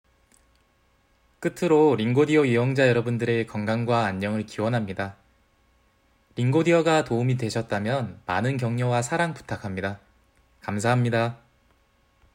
(He also recorded his responses at a normal speaking speed and tone.)